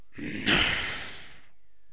bull_select1.wav